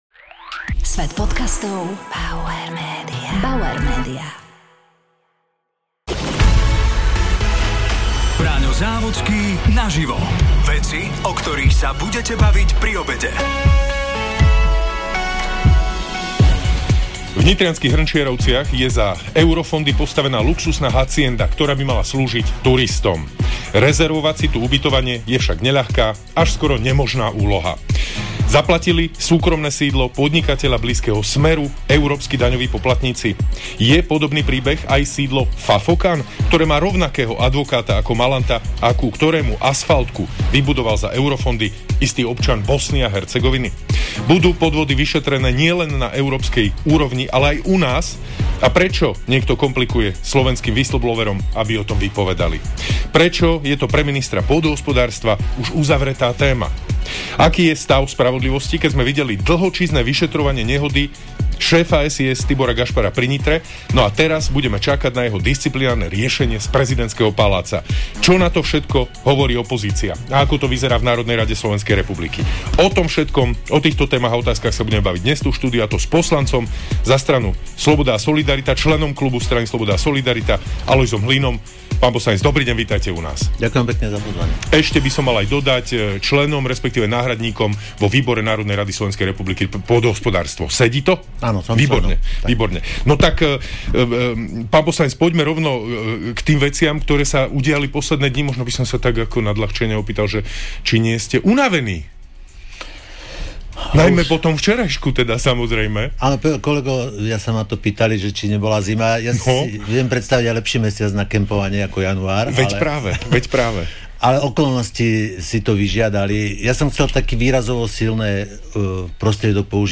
sa rozprával s poslancom za SaS aj bývalým predsedom KDH Alojzom Hlinom.